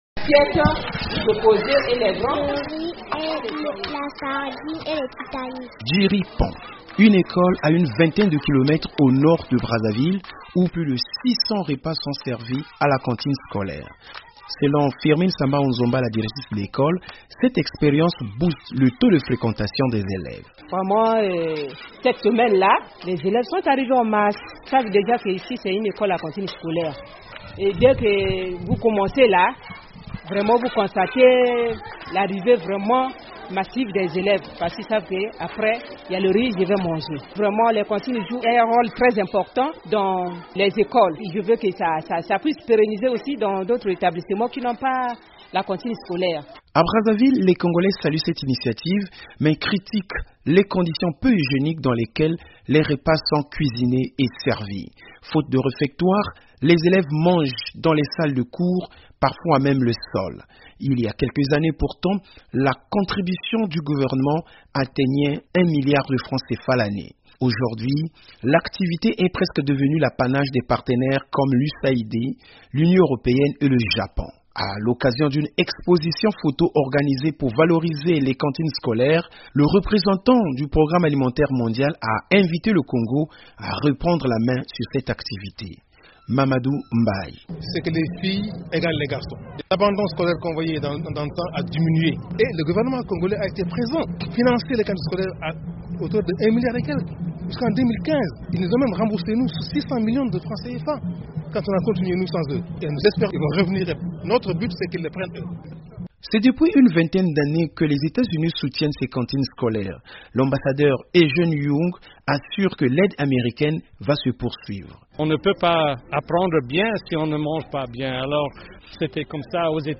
Au Congo-Brazzaville, l'expérience des cantines scolaires fait l'objet d'une exposition photos organisée par le Programme alimentaire mondial, au cours de laquelle le gouvernement a été invité à reprendre en main cette activité. Reportage